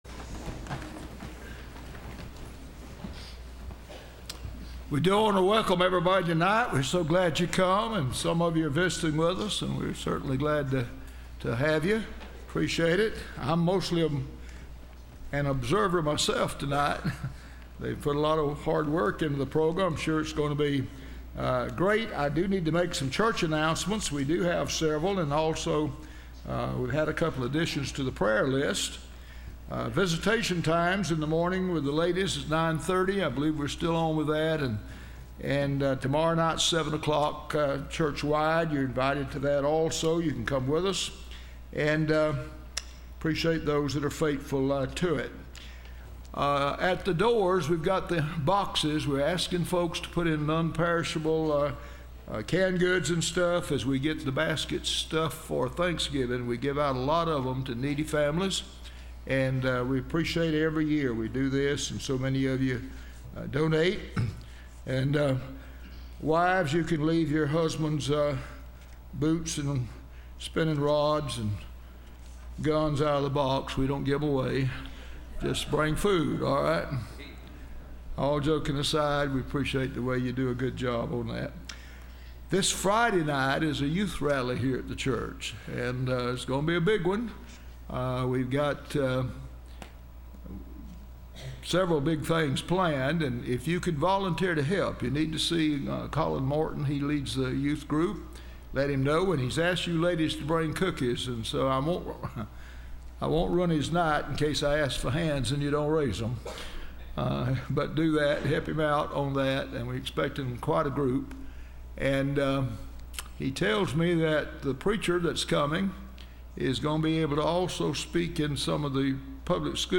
Patriotic Music Service – Landmark Baptist Church
Choir